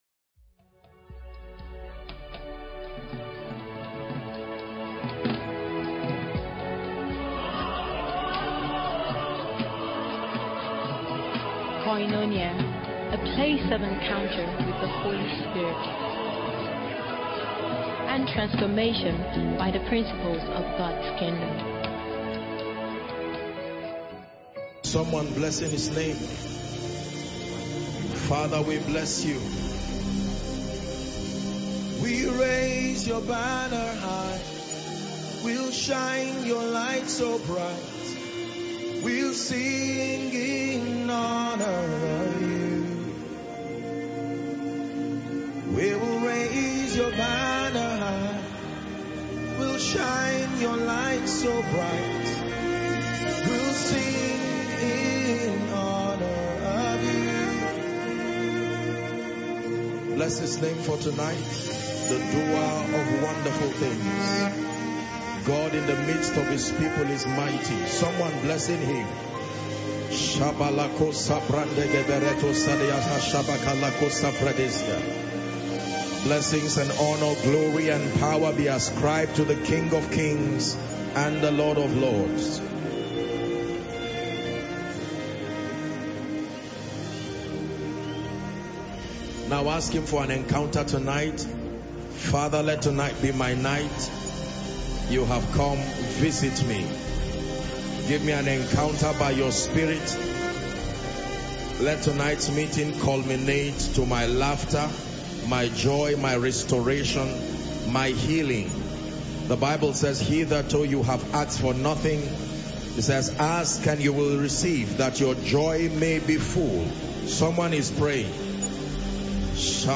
Koinonia Miracle service is a monthly programme organized by the Eternity Network International (ENI).